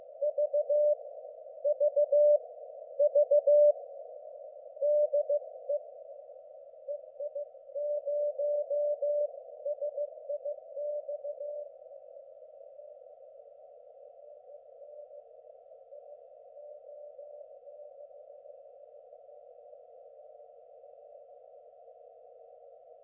Die hier aufgeführten Stationen wurden von mir selbst empfangen.